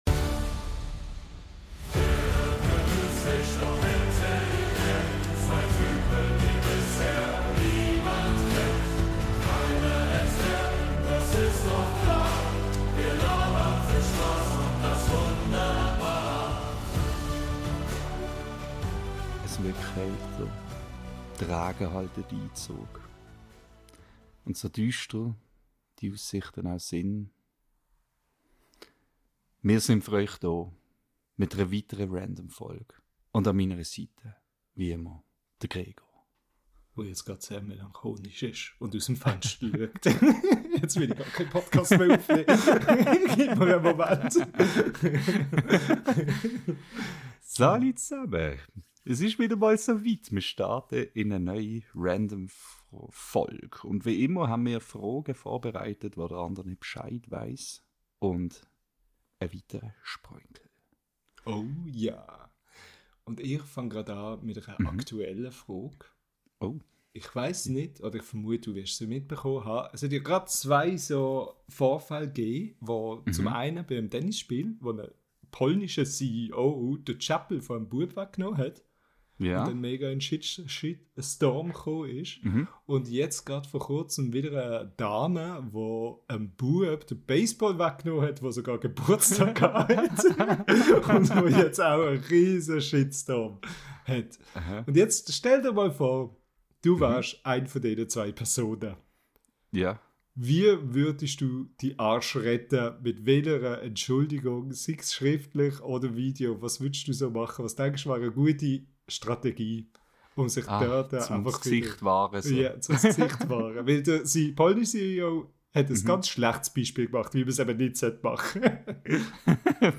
Wir ranken diesmal die unangenehmsten Eigenschaften von Häusern, küren die unpassendsten Songs für eine Beerdigung und klären endlich die eine Frage, die die Menschheit seit Jahrhunderten spaltet: Wie viel Ketchup gehört wirklich auf die Pommes und vor allem wo? Also hört rein, lacht mit uns und lasst euch wieder einmal ordentlich randomisieren – natürlich auf Schweizerdeutsch.